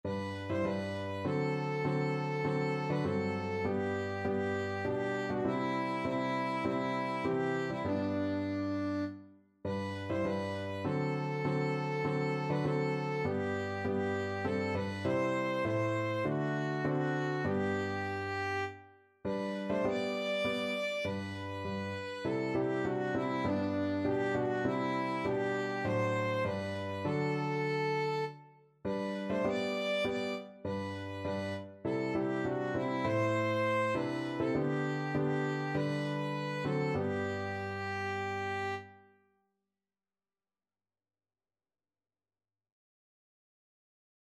Christian
4/4 (View more 4/4 Music)
D5-D6
Violin  (View more Easy Violin Music)
Classical (View more Classical Violin Music)